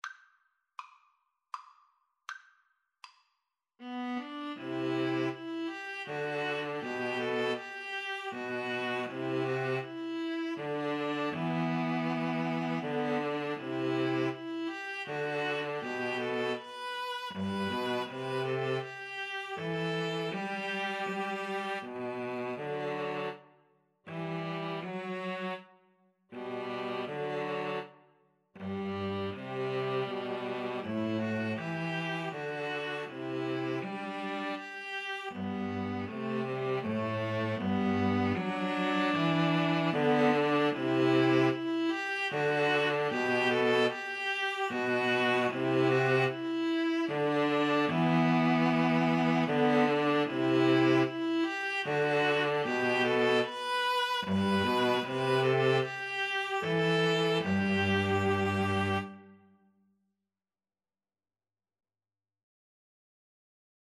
Play (or use space bar on your keyboard) Pause Music Playalong - Player 1 Accompaniment Playalong - Player 3 Accompaniment reset tempo print settings full screen
G major (Sounding Pitch) (View more G major Music for String trio )
3/4 (View more 3/4 Music)
Andante
String trio  (View more Easy String trio Music)
Classical (View more Classical String trio Music)